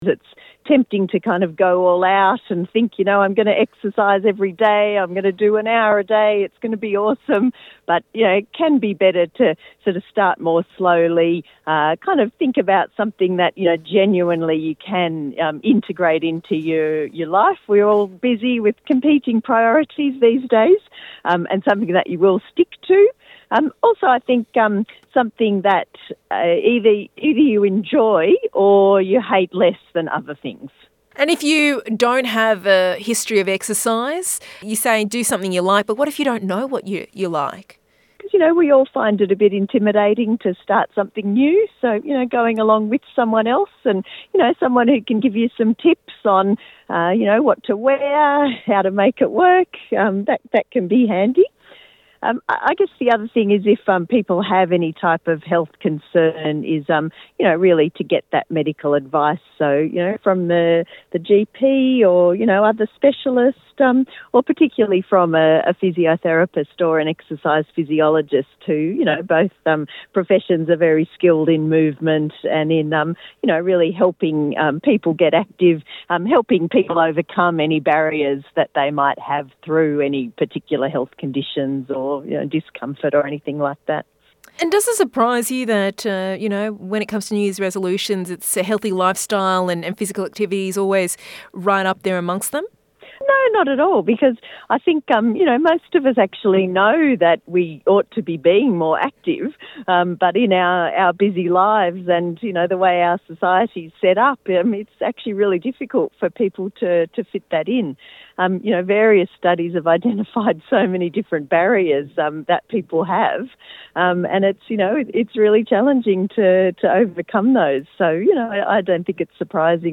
Headlines on health